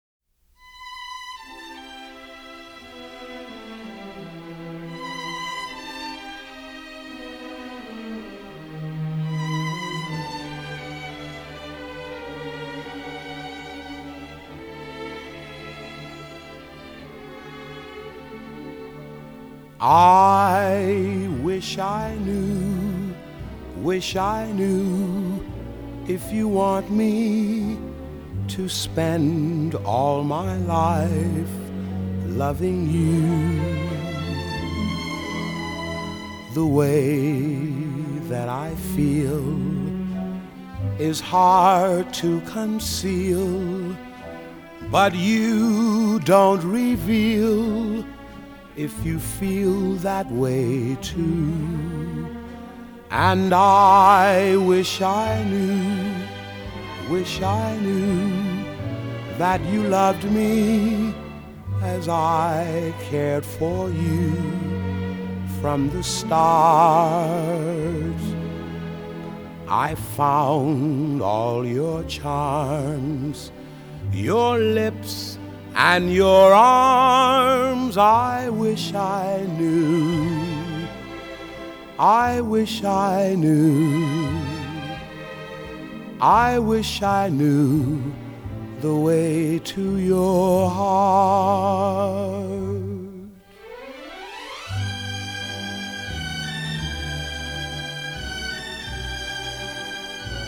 用深情無比的嗓音融化您的心！
採用原始類比母帶以最高音質之 45 轉 LP 復刻！